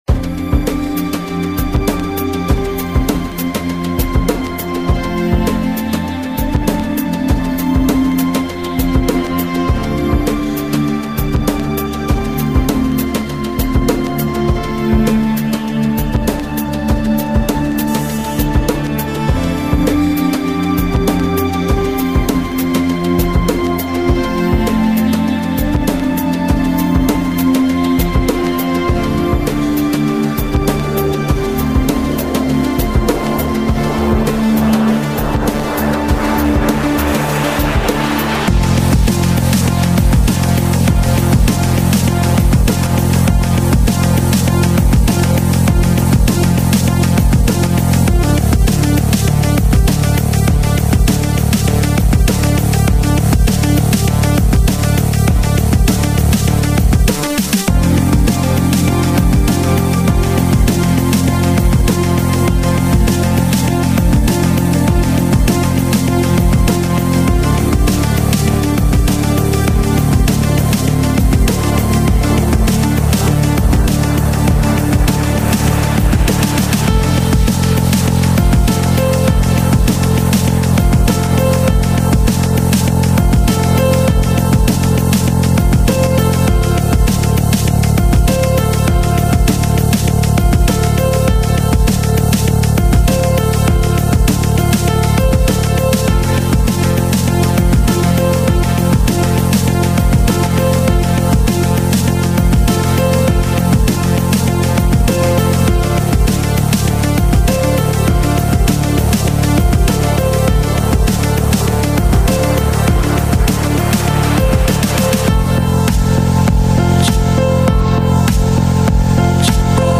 were established in 2013 as an electronic project